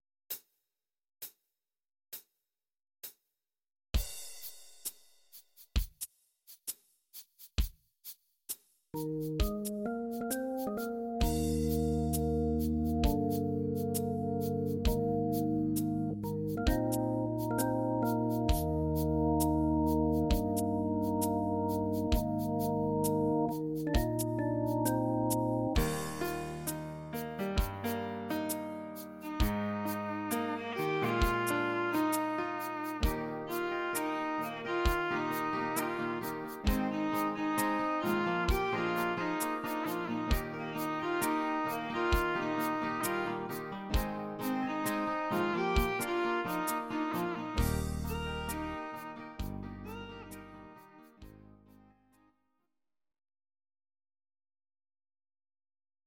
Please note: no vocals and no karaoke included.
Your-Mix: Country (822)